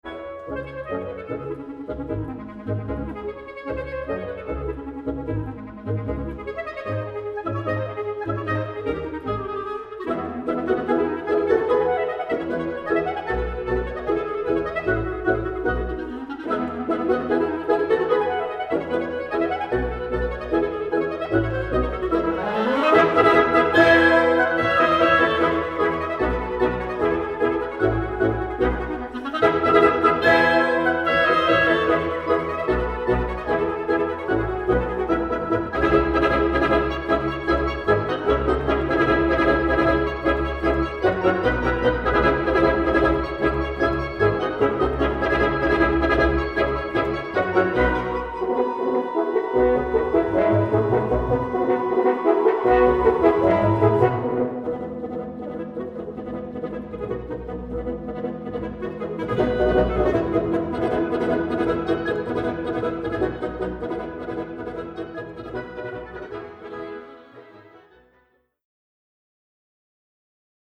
Historic transcriptions for classical wind ensemble